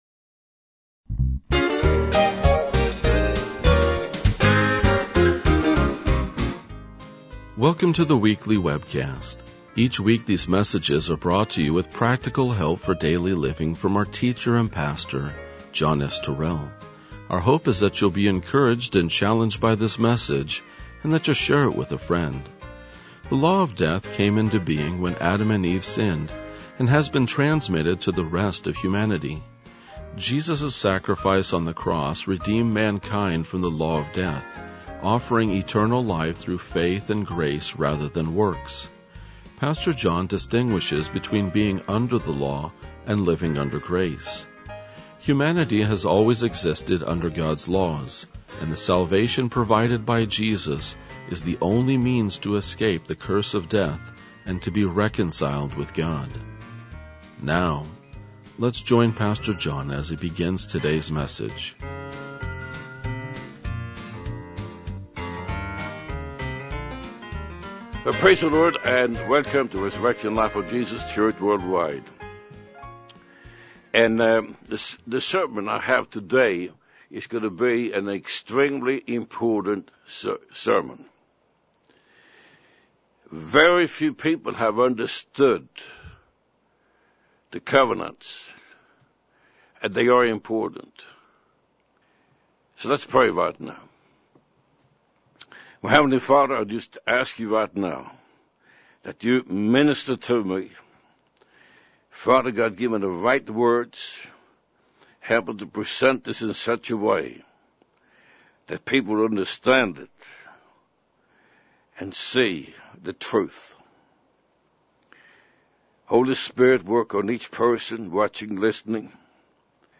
RLJ-2039-Sermon.mp3